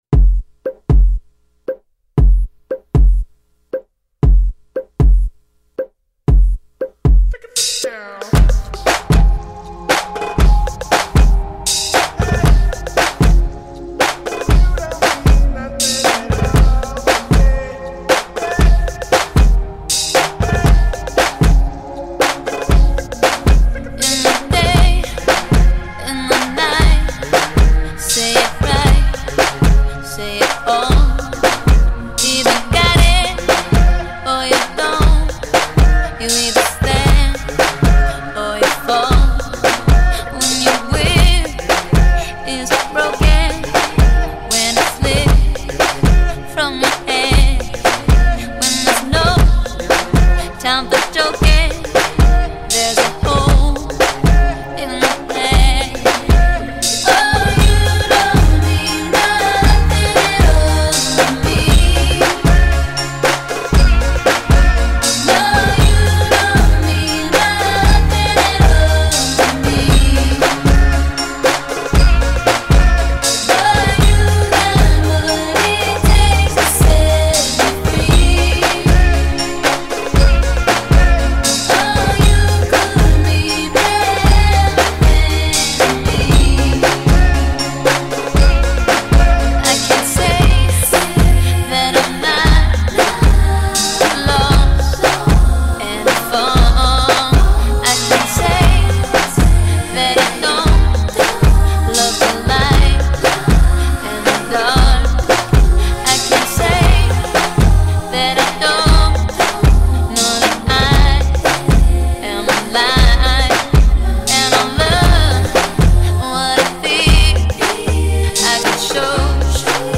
Highly gifted vocalist